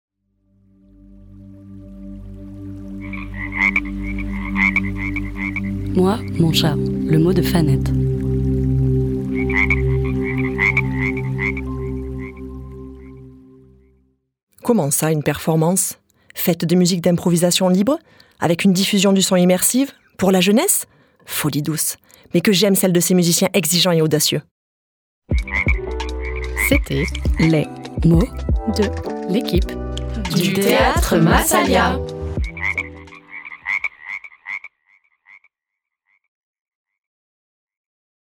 Performance musicale immersive, dès 7 ans
MUSIQUE IMPROVISÉE ET FÉLINE